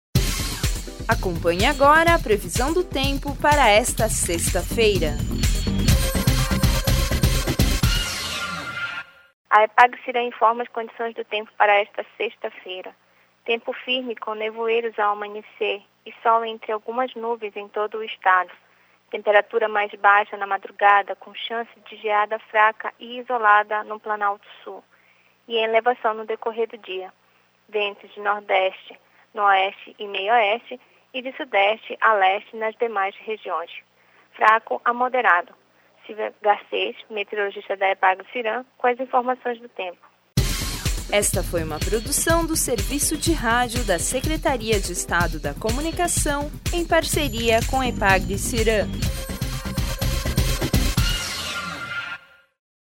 Previsão do tempo para sexta-feira, 27/09/2013